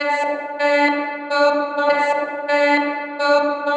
Female vocals Free sound effects and audio clips
• chopped vocals 109-127 female 1 - Dm - 95.wav